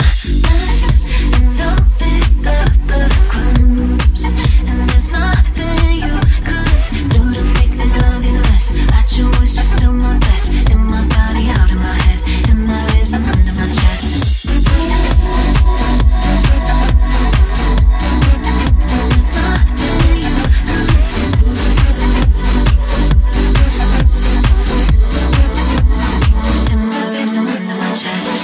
Genere: deep,dance,news